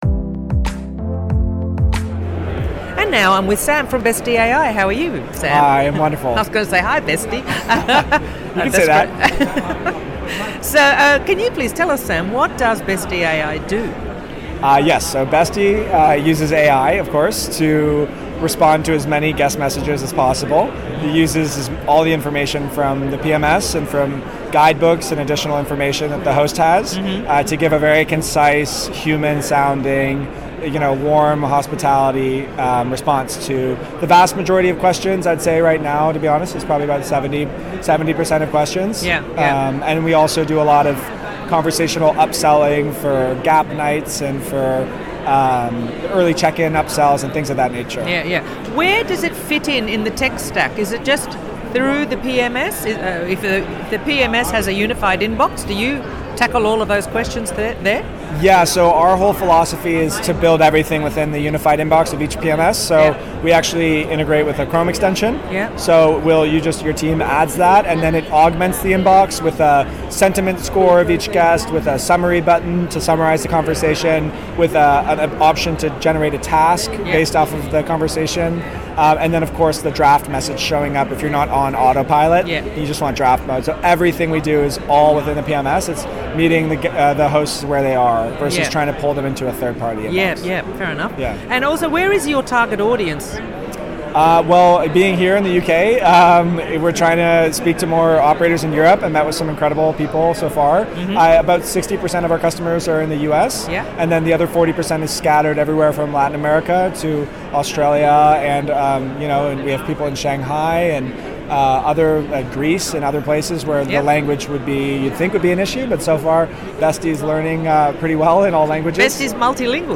Location: The Short Stay Summit, London, April 2024 Check out all the interviews...